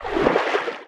Sfx_creature_babypenguin_swim_barrel_roll_03.ogg